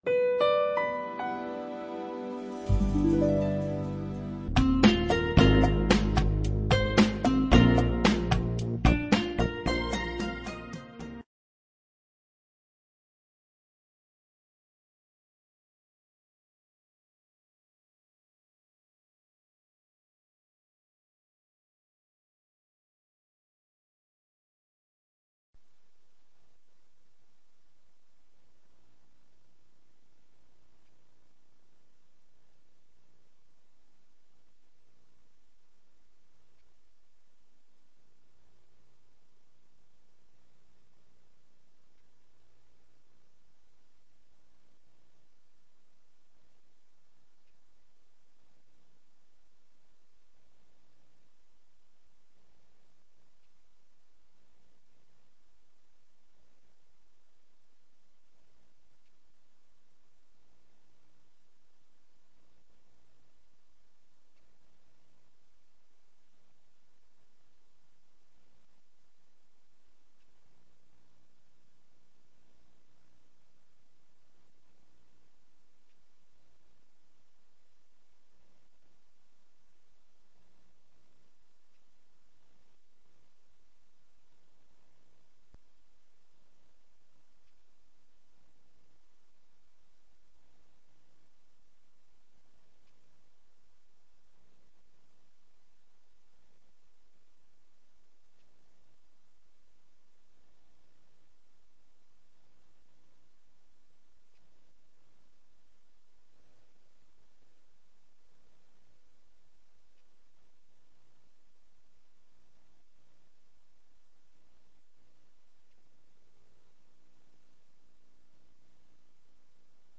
imparte esta conferencia en el auditorio del Hospital de Santiago de Úbeda dentro de la "44 Semana Sanjuanista"